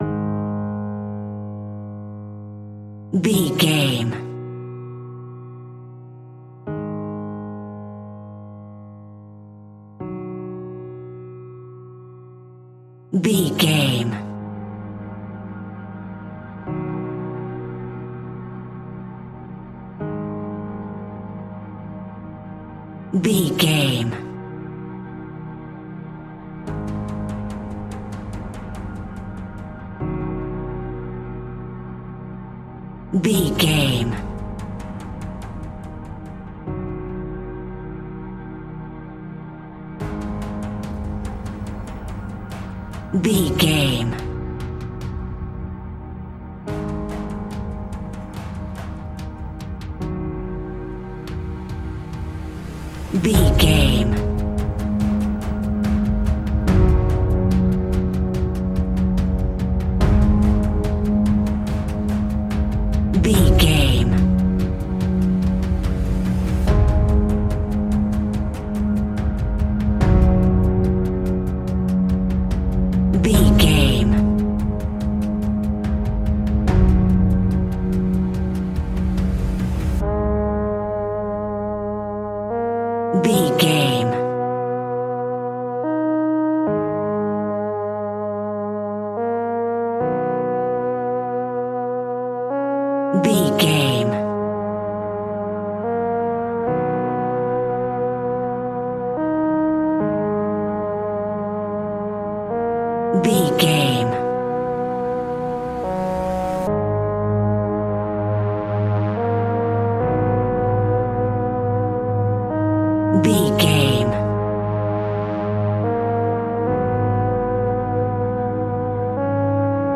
Aeolian/Minor
A♭
ominous
dark
haunting
eerie
piano
synthesizer
horror music
Horror Pads
Horror Synths